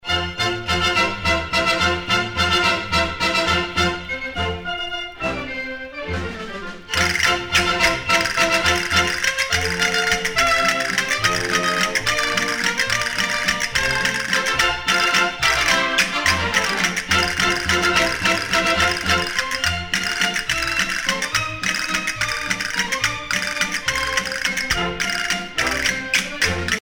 danse : sevillana
Pièce musicale éditée